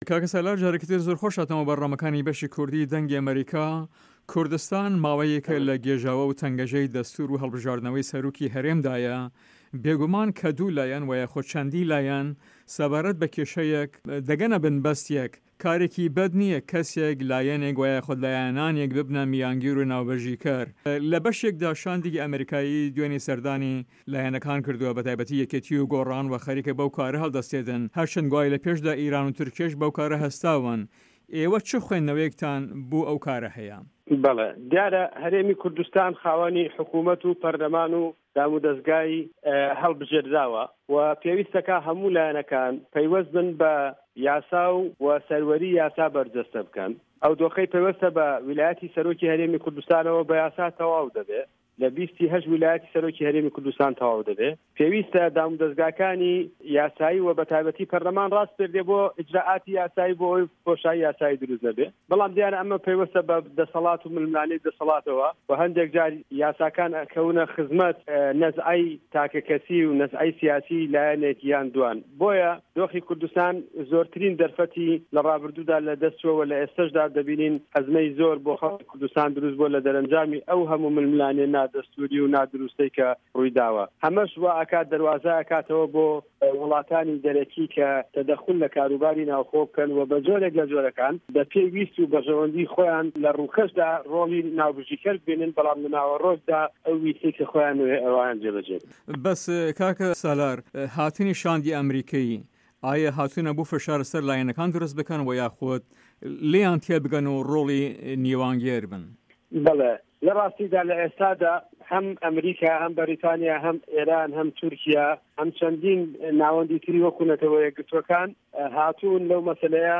سالار مه‌حمود ئه‌ندام په‌رله‌مانی هه‌رێمی کوردستان له‌ هه‌ڤپه‌یڤینێکدا له‌گه‌ڵ به‌شی کوردی ده‌نگی ئه‌مه‌ریکا ده‌ڵێت" دیاره‌ هه‌رێمی کوردستان خاوه‌نی حکومه‌ت و په‌رله‌مان و دام و ده‌زگای هه‌ڵبژێردراوه‌ وه‌ پیویست ده‌کات هه‌موو لایه‌نه‌کان پابه‌ند بن به‌ یاسا و سه‌روه‌ری یاسا به‌رجه‌سته‌ بکه‌ن، ئه‌و دۆخه‌ی په‌یوه‌ندی به‌ ولایه‌تی سه‌رۆکی هه‌رێمی کوردستانه‌وه‌ هه‌یه‌ به‌ یاسا ته‌واو ده‌بێ، له‌ 20/8 ولایه‌تی سه‌رۆکی هه‌رێمی کوردستان ته‌واو ده‌بێت پێویسته‌ دام و ده‌زگاکانی یاسایی وه‌ به‌ تاێبه‌تی په‌رله‌مان راسپێردێت بۆ ئیجرائاتی یاسایی بۆ ئه‌وه‌ی بۆشایی یاسایی درووست نه‌بێت.